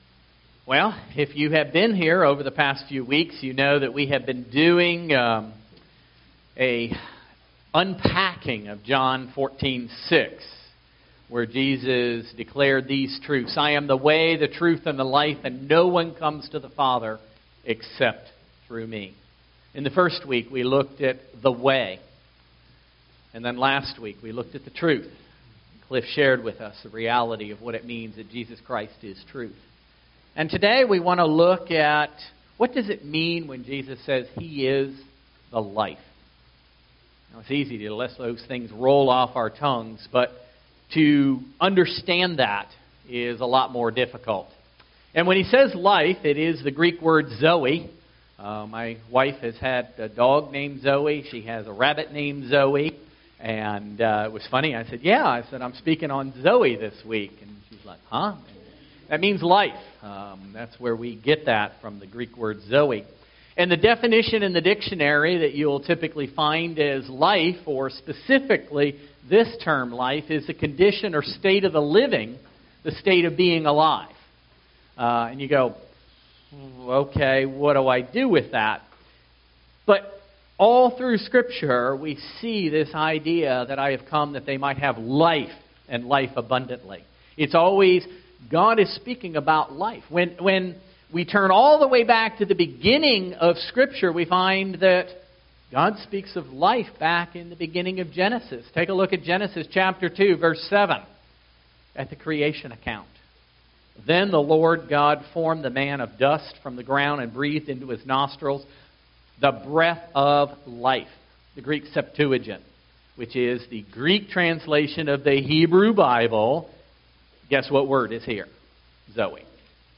Matthew 28:16-20 Service Type: Sunday Morning Worship